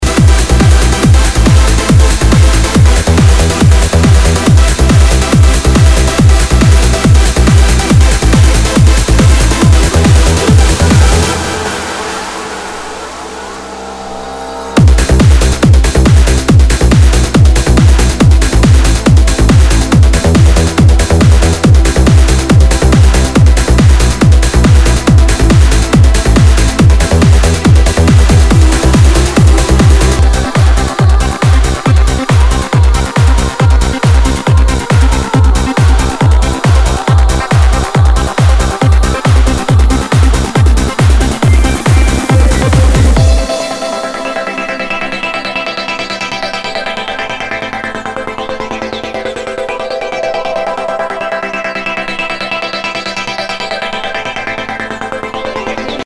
HOUSE/TECHNO/ELECTRO
ナイス！トランス！